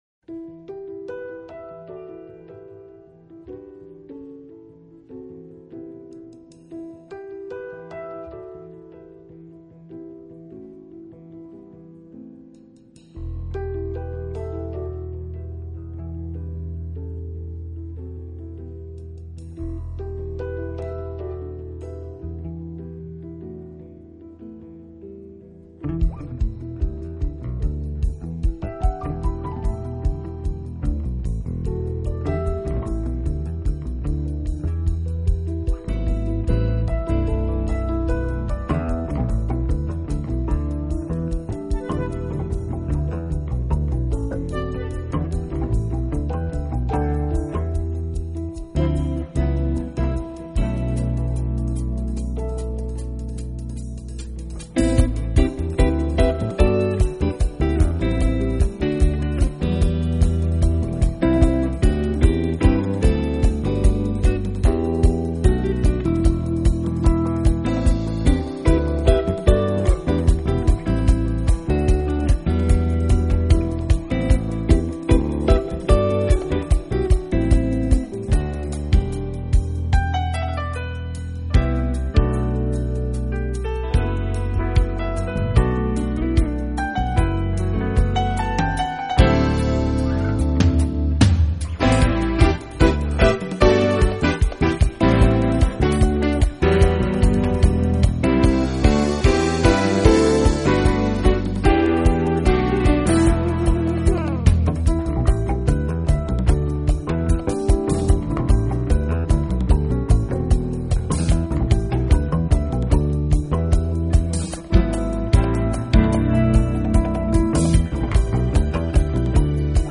音乐类型:Smooth Jazz, Crossover Jazz, Jazz-Pop
充滿活力的